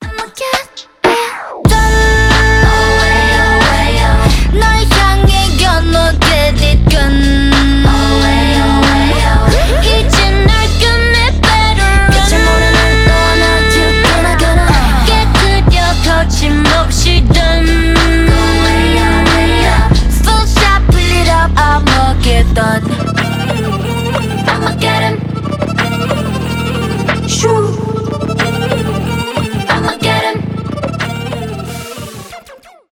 k-pop
поп
trap